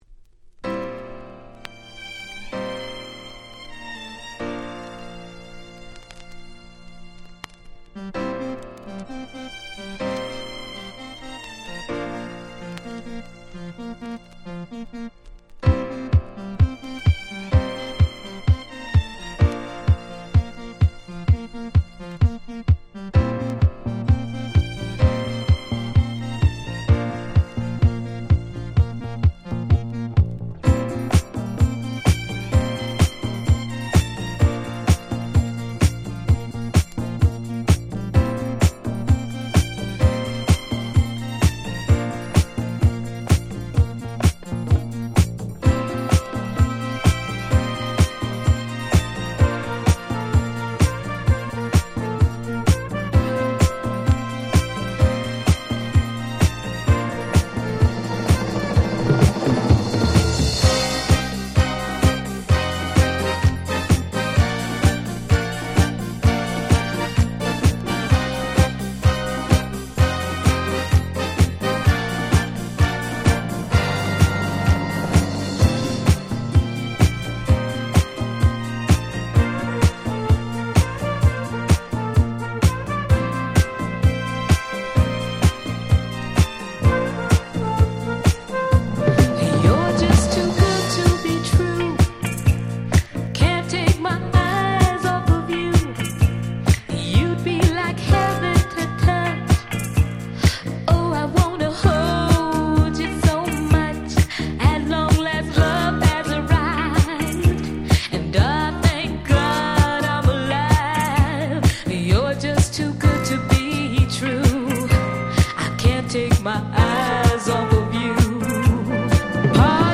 【Media】Vinyl 12'' Single